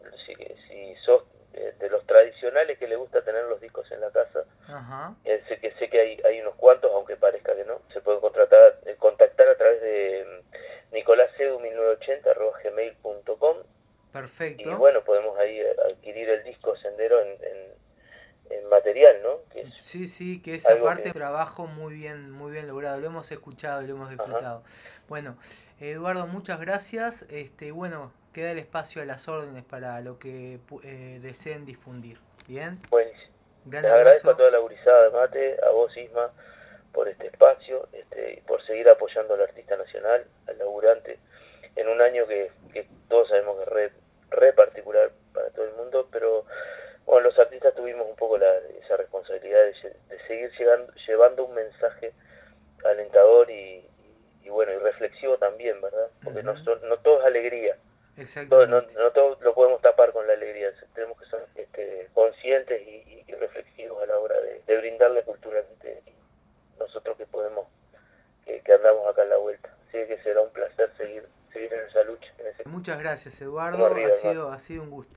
Y compartimos el resto de la entrevista